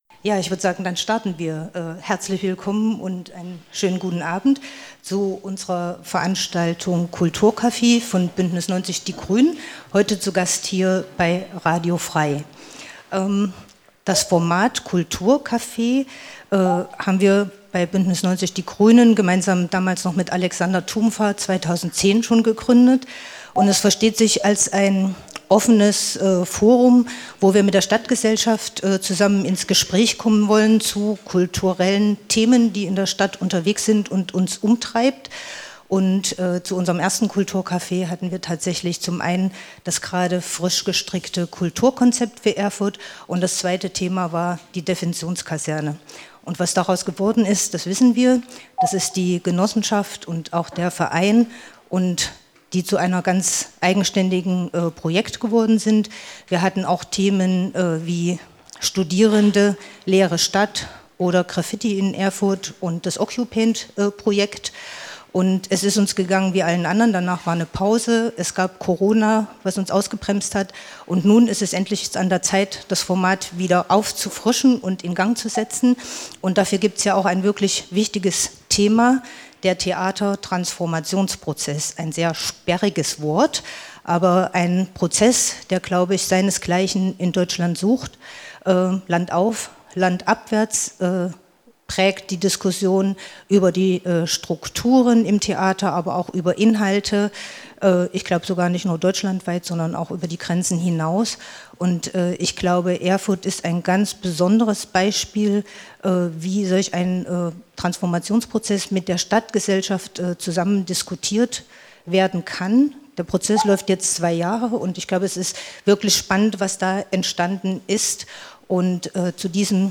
Mitschnitt online | Informationsabend zum Theatertransformationsprozess | 23.08.2023 F.R.E.I.fläche